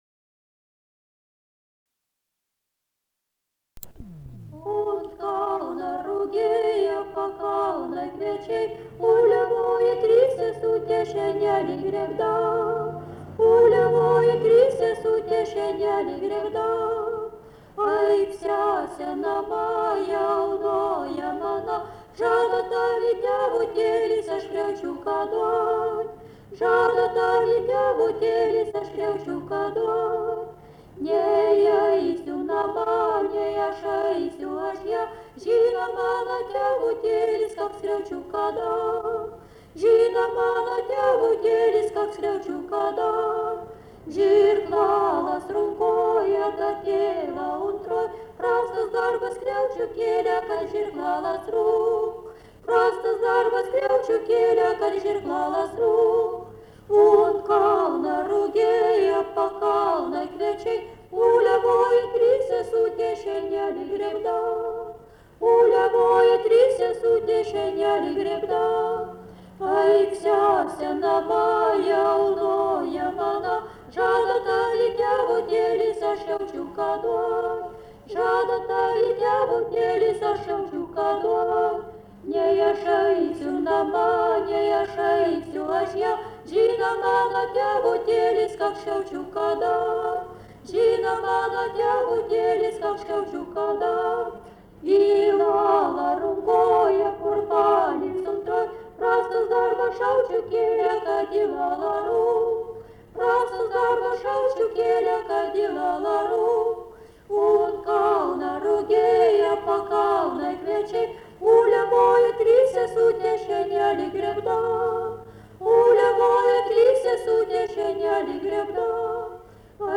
Dalykas, tema daina
Erdvinė aprėptis Juodšiliai Vilnius
Atlikimo pubūdis vokalinis